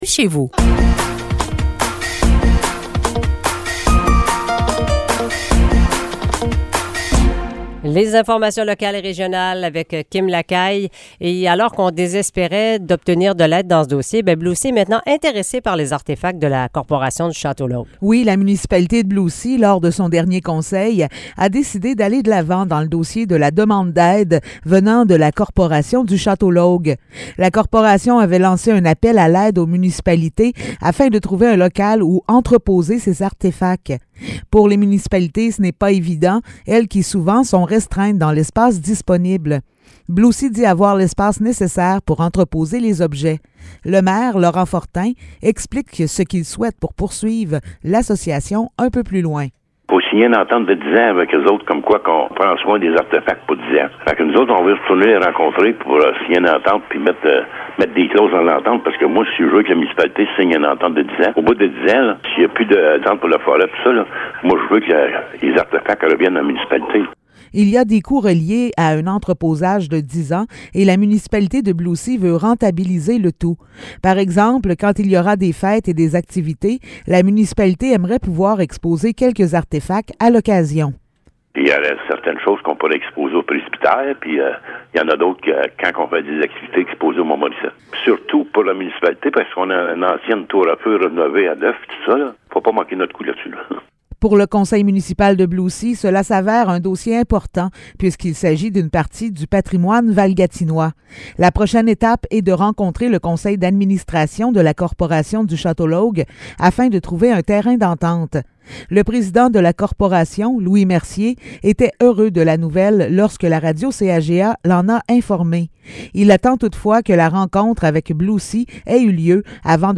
Nouvelles locales - 21 février 2023 - 8 h